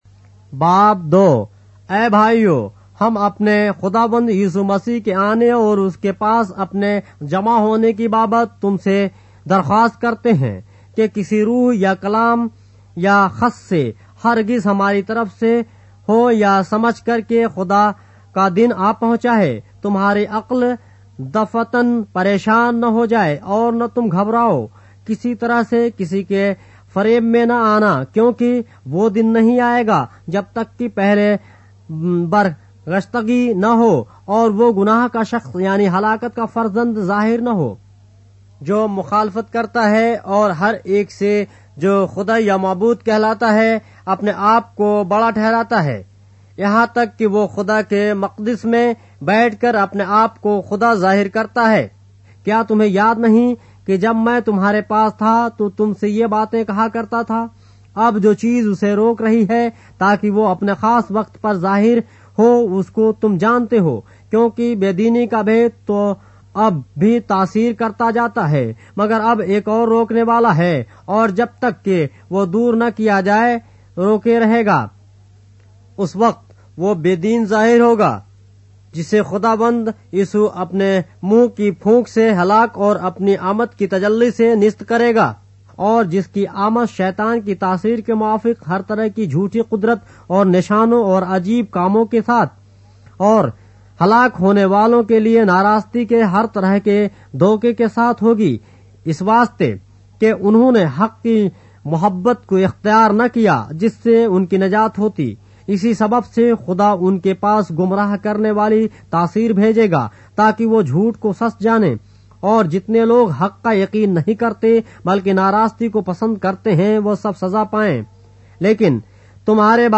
اردو بائبل کے باب - آڈیو روایت کے ساتھ - 2 Thessalonians, chapter 2 of the Holy Bible in Urdu